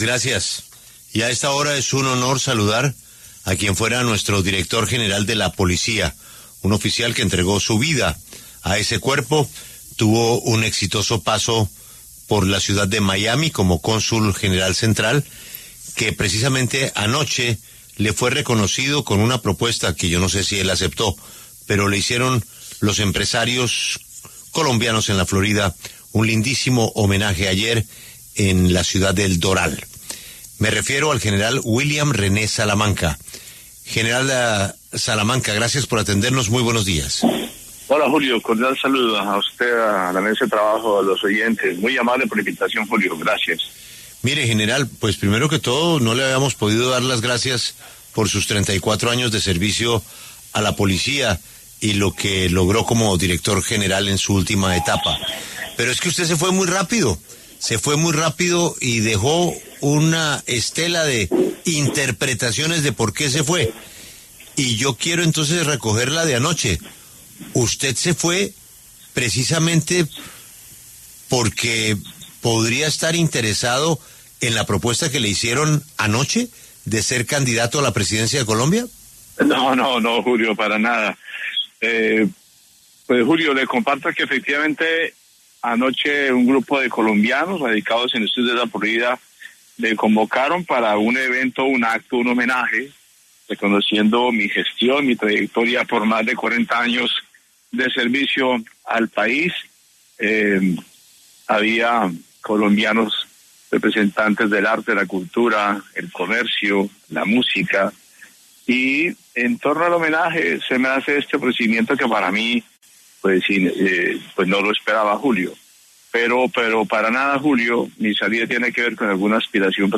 El general (r) William Salamanca, exdirector de la Policía Nacional, conversó con La W sobre una posible candidatura presidencial para las elecciones del 2026.